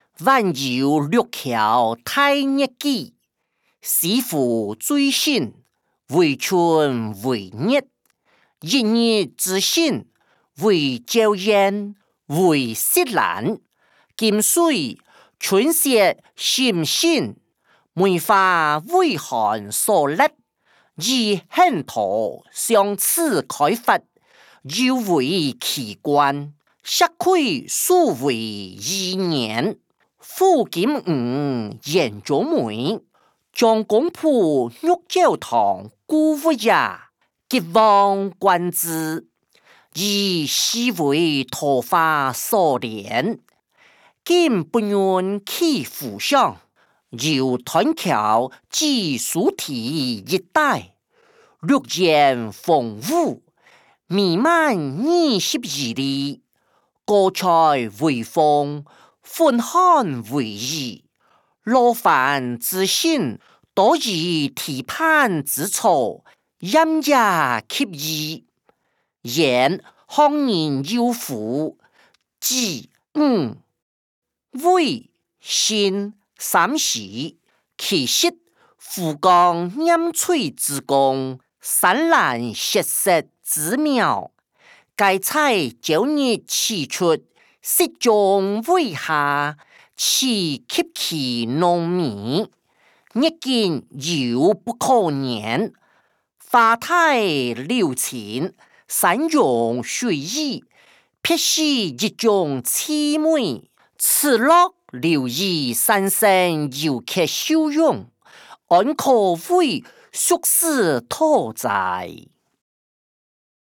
歷代散文-晚遊六橋待月記音檔(大埔腔)